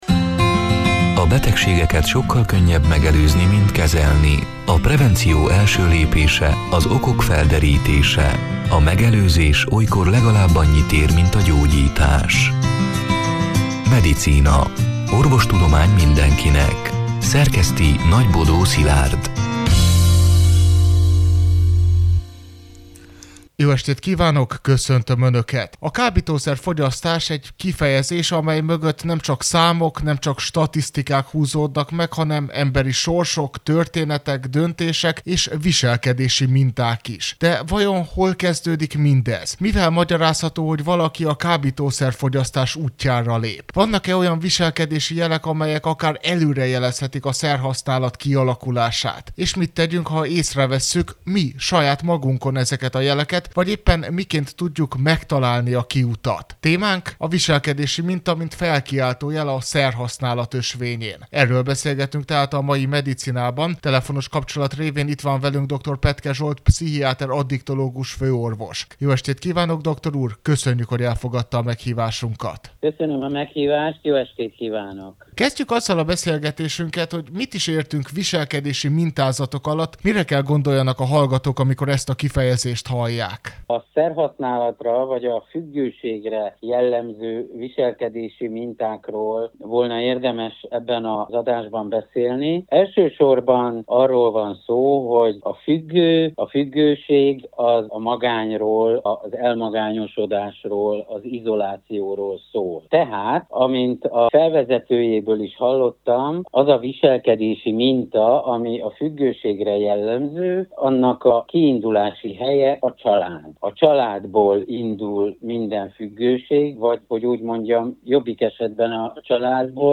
A Marosvásárhelyi Rádió Medicina (elhangzott: 2025. július 16-án, szerdán este nyolc órától) c. műsorának hanganyaga: